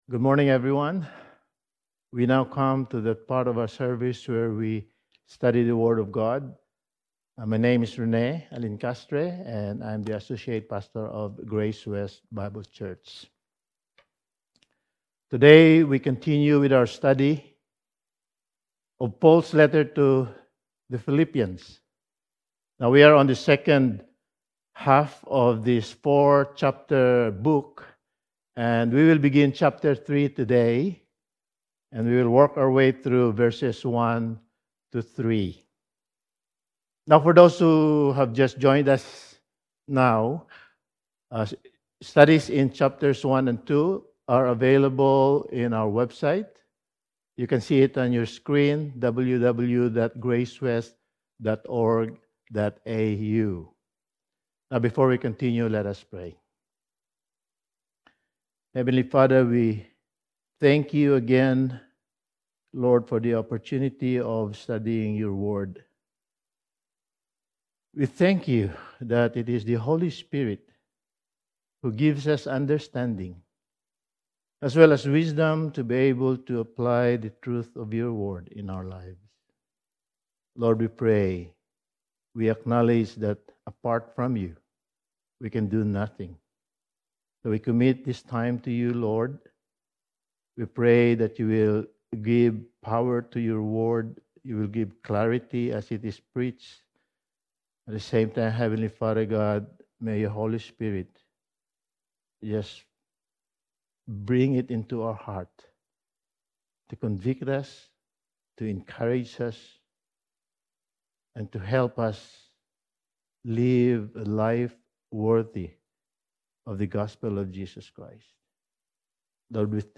Passage: Philippians 3:1-3 Service Type: Sunday Morning